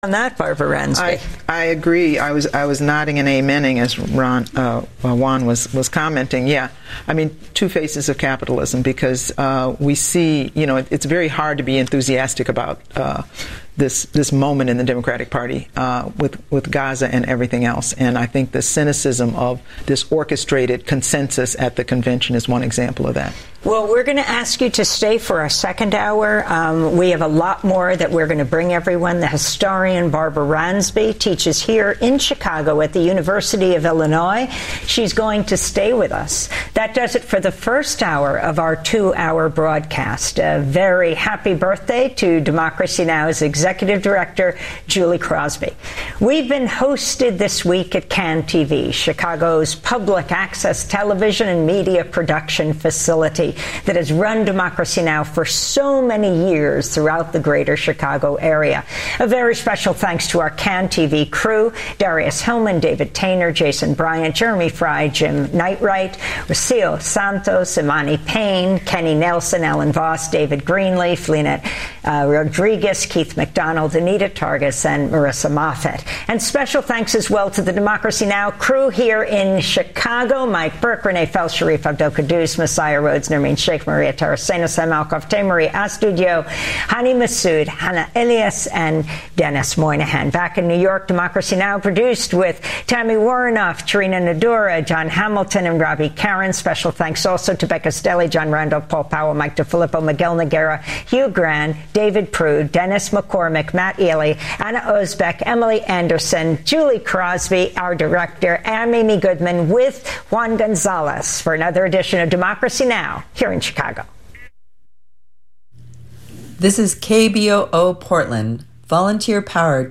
The Gap is talk radio aimed at bridging the generational divide.